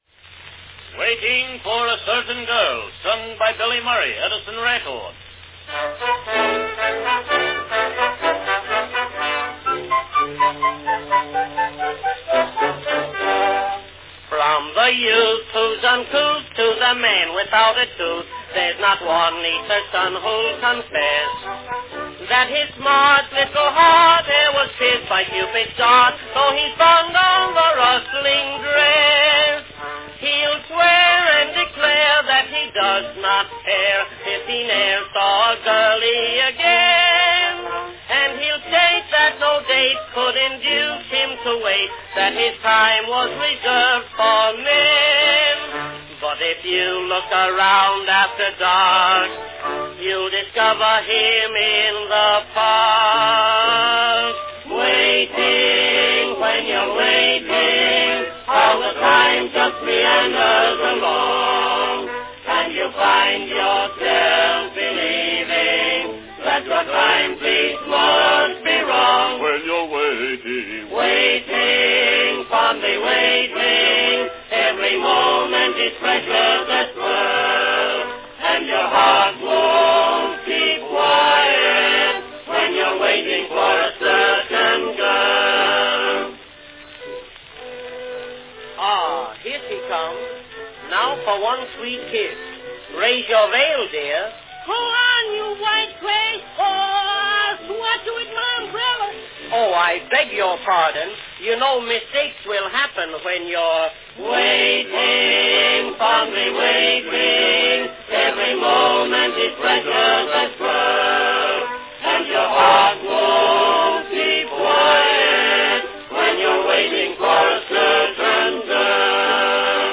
Category Song and chorus
serio-comic song